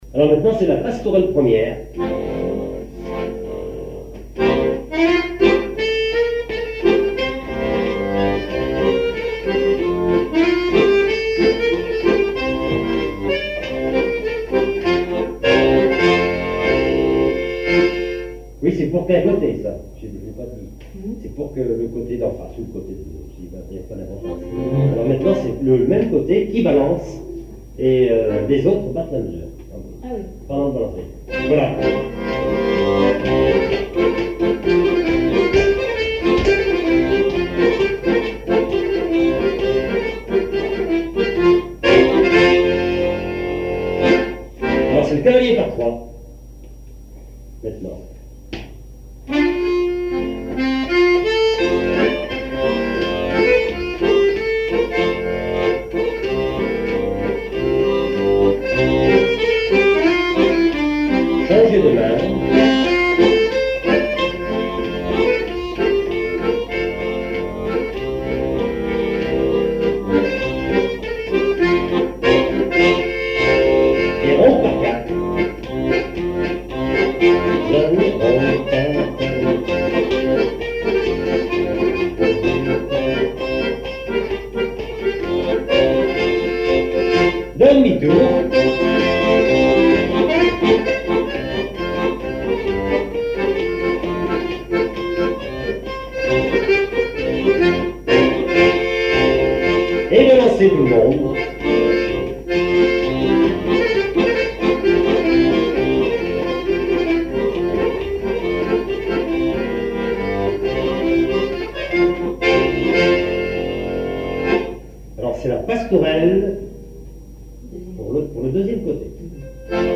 Mémoires et Patrimoines vivants - RaddO est une base de données d'archives iconographiques et sonores.
danse : quadrille : pastourelle
répertoire à l'accordéon diatonique
Pièce musicale inédite